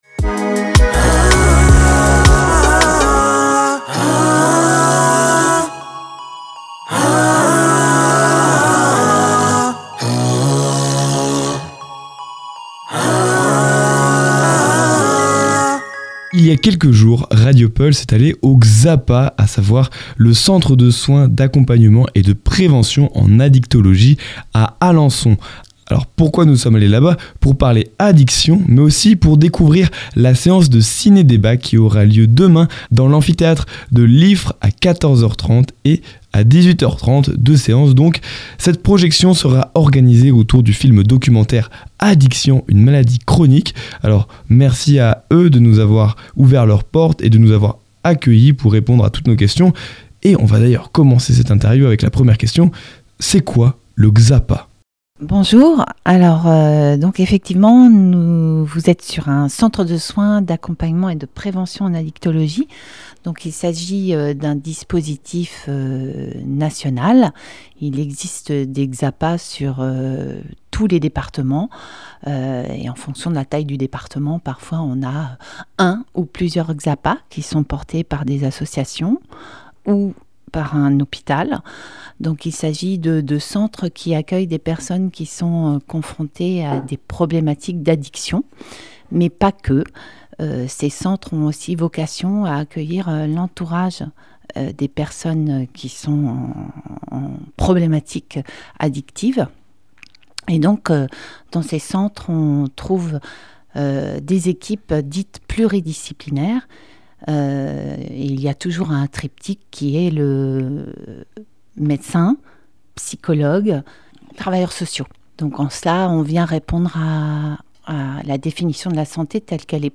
Dans l'émission C'est de la Local du 2 juin, nous avons eu le plaisir de recevoir trois membres du CSAPA (Centre de soins, d’accompagnement et de prévention en addictologie) d'Alençon. Elles sont venues toutes les trois pour répondre à nos questions autour de l'addiction et des solutions que le CSAPA propose en termes de prévention et de traitement.